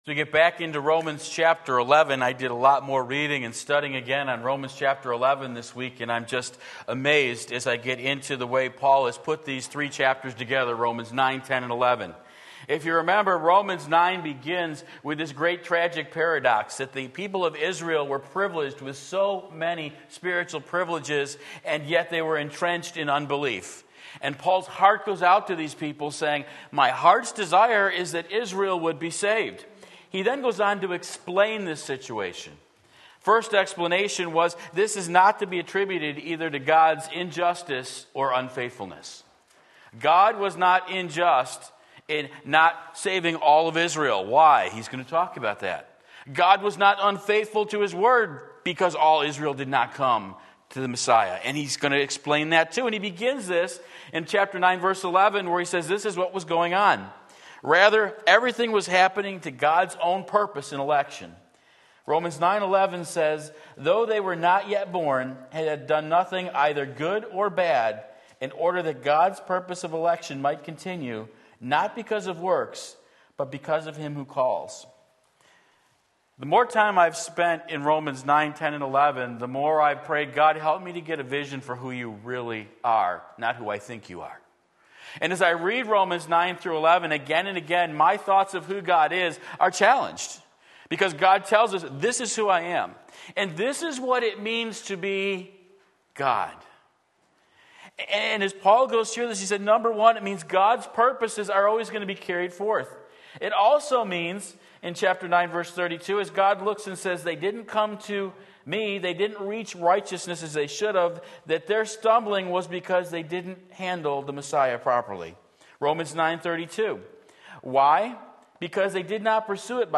Sermon Link
Implication of Israel's Stumbling Romans 11:7-12 Sunday Morning Service